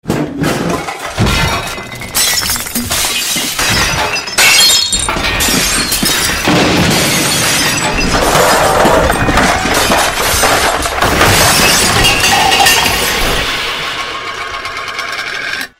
Stuff Falling Down Legacies Botón de Sonido